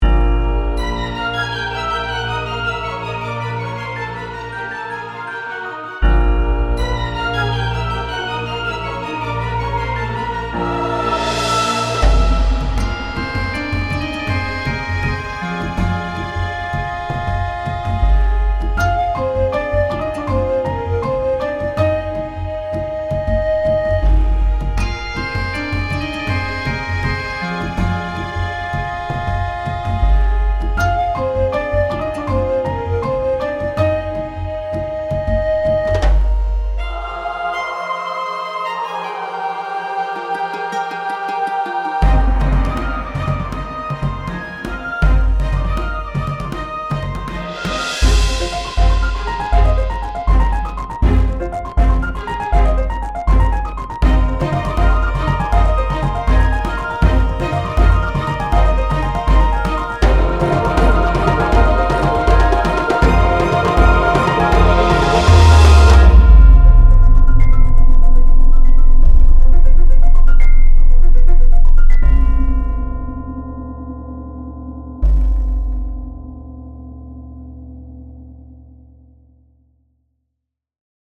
古代文明を彷彿とさせる伝記的なBGM
ワールド 1:21